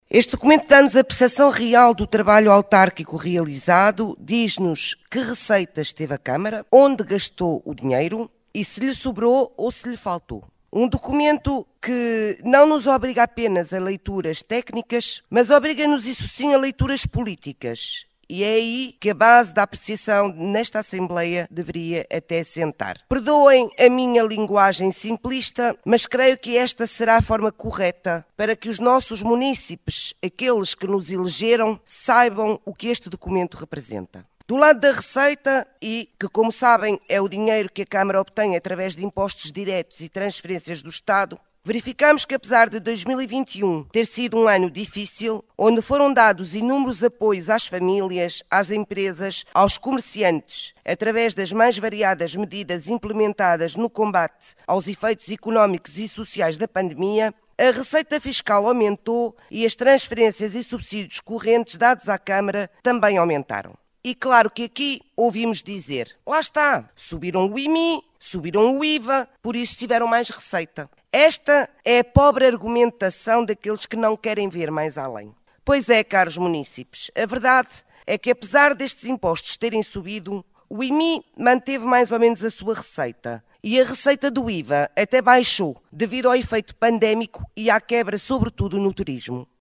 O relatório de contas relativo ao ano de 2021 foi aprovado por maioria na última Assembleia Municipal de Caminha.
Paula Aldeia, Partido Socialista